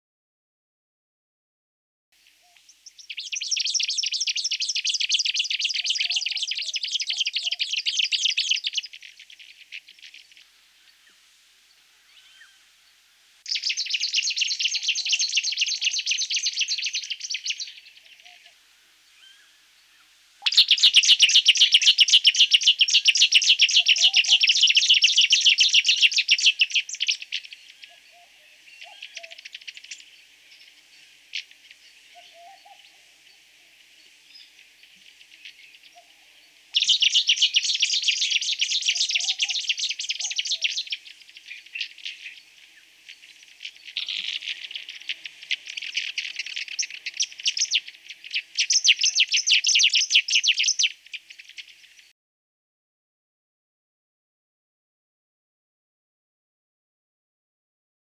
Unique Australian Bird Sounds
purple crowned fairy wren
18-purple-crowned-fairy-wren.mp3